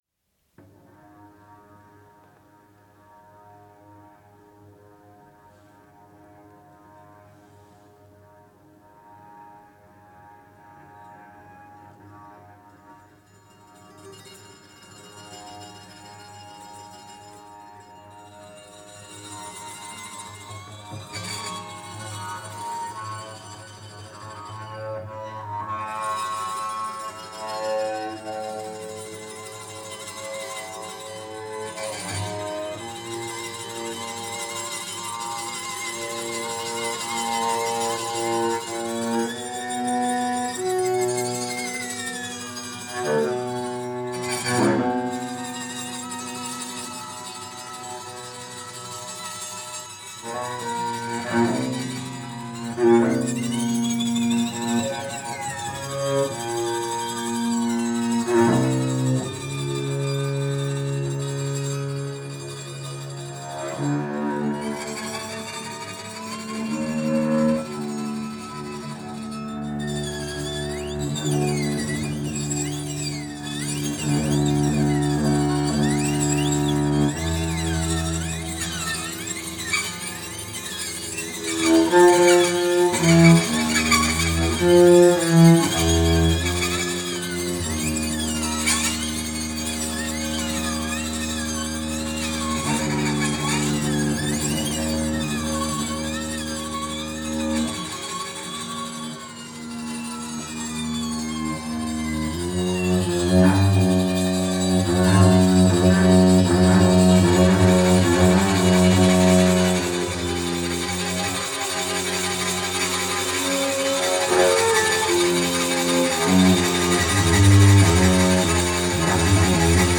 guitar
cello